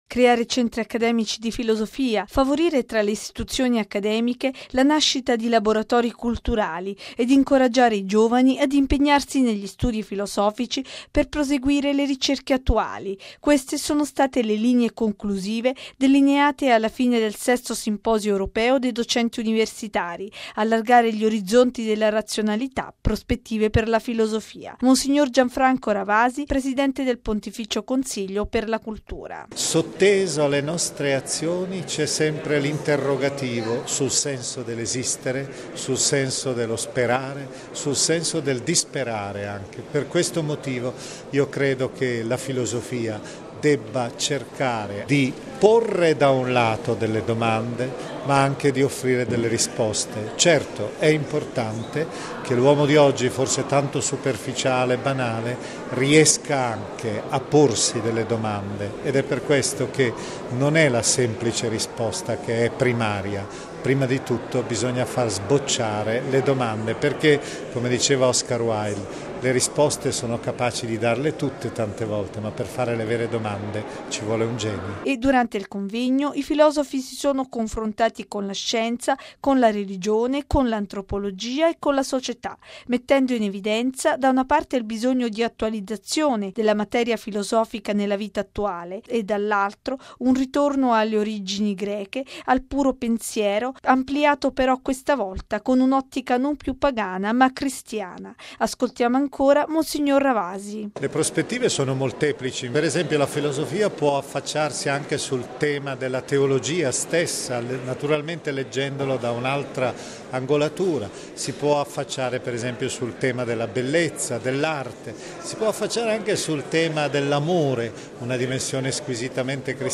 L'intervento di mons. Ravasi conclude il Simposio europeo dei docenti universitari